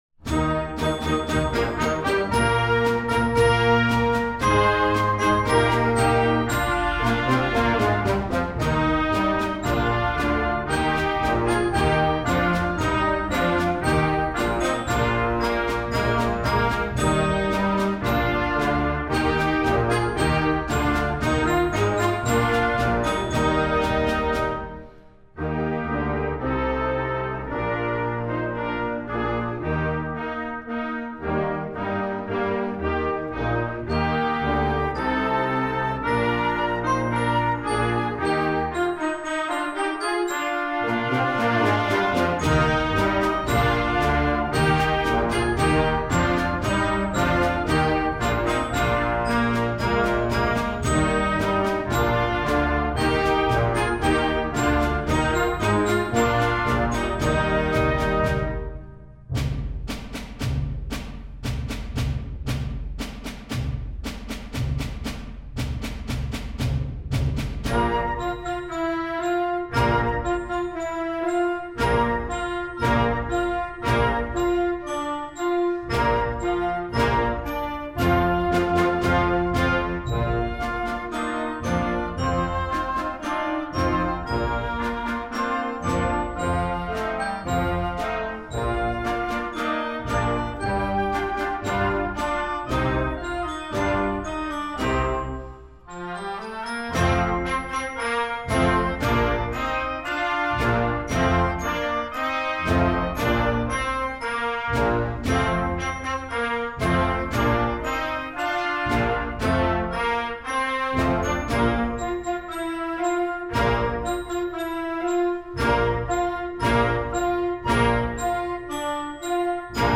classical, children